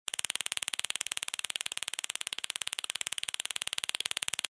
На этой странице собраны звуки электрошокера – от резких разрядов до характерного жужжания.
Звук електрошокера